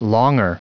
Prononciation du mot : longer